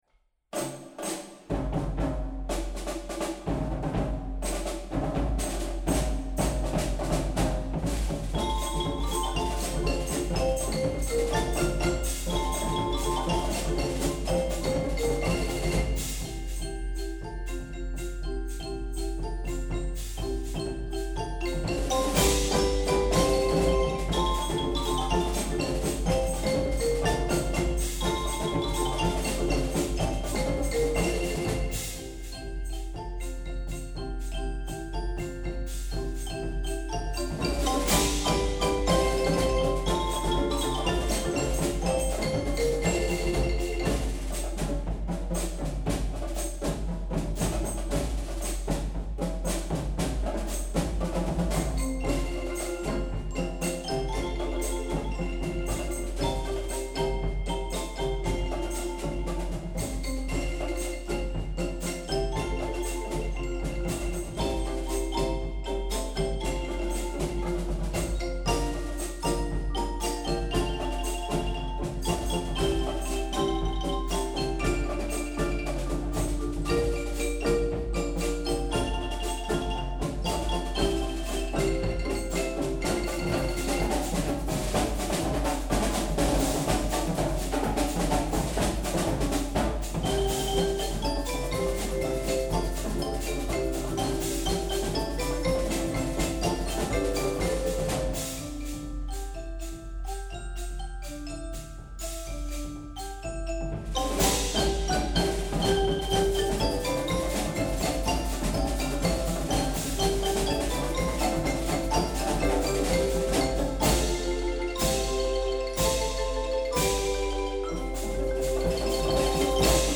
Slagwerkensemble
11-Stemmig